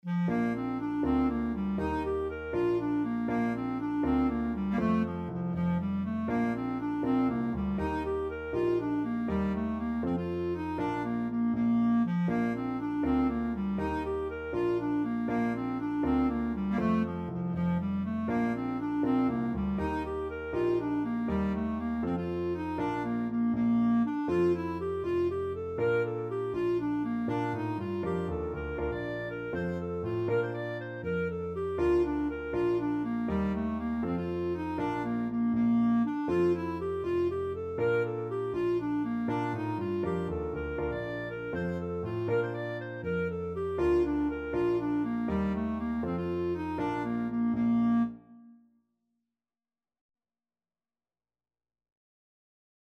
Clarinet
Bb major (Sounding Pitch) C major (Clarinet in Bb) (View more Bb major Music for Clarinet )
6/8 (View more 6/8 Music)
Traditional (View more Traditional Clarinet Music)
Irish